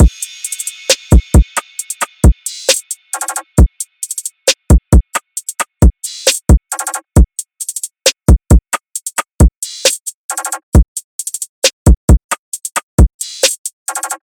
DRUM LOOPS
Values (134 BPM – Bm)
UNISON_DRUMLOOP_Values-134-BPM-Bm.mp3